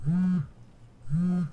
sfx_phone.ogg